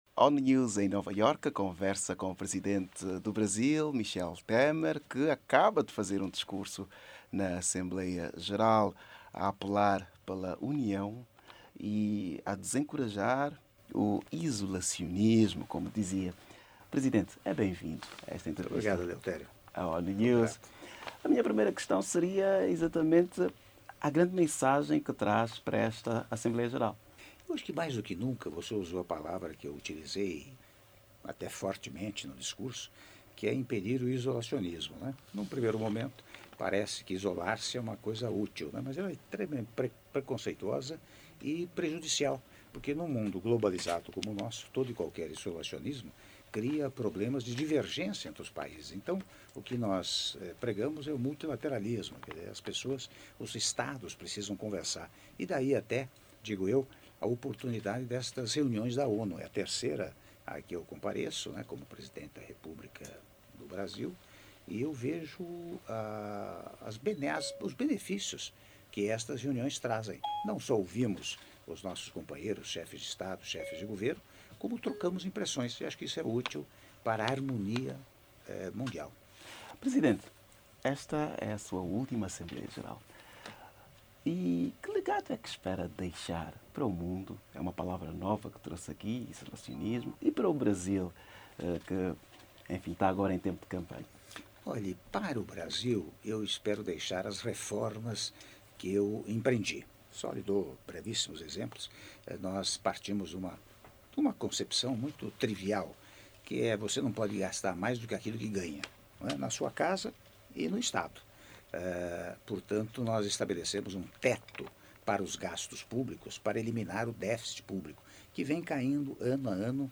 Áudio da entrevista exclusiva concedida pelo Presidente da República, Michel Temer, à ONU News - Nova Iorque/EUA (06min48s)